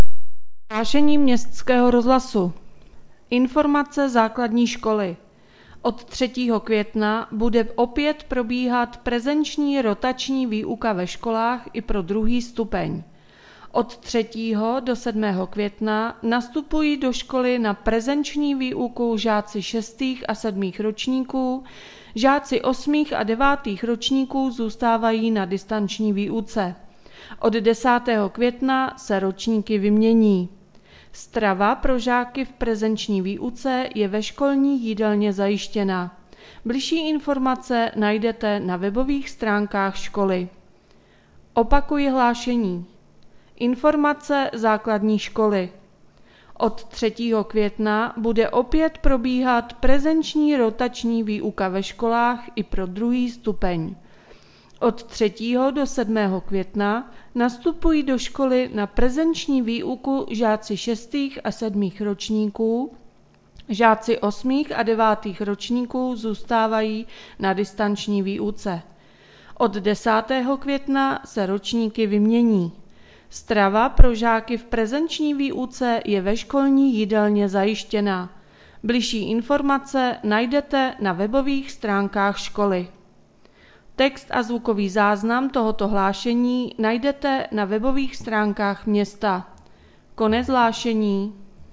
Hlášení městského rozhlasu 30.4.2021